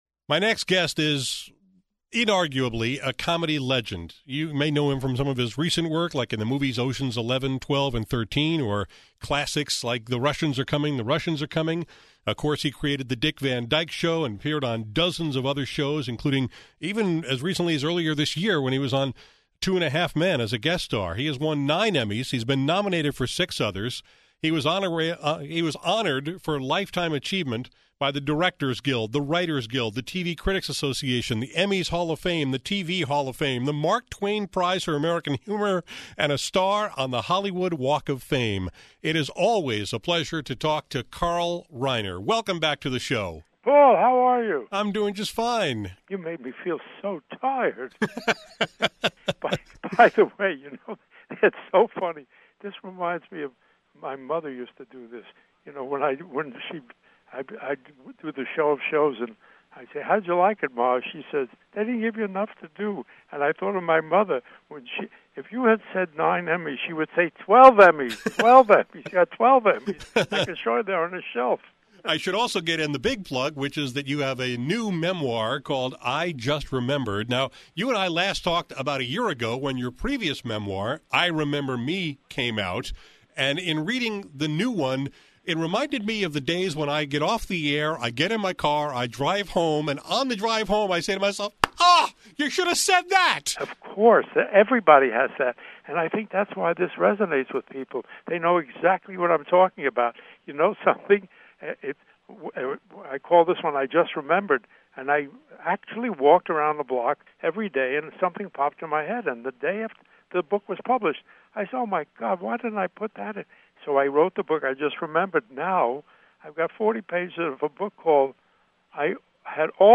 I always enjoy talking with Carl, so when his new memoir, “I Just Remembered,” was published last week, I invited him to talk about it and tell some stories on my radio show. For a man of 92, who has been in show business his entire adult life, he has a remarkable memory and his timing is still perfect.
Among the topics we discussed in our extended conversation: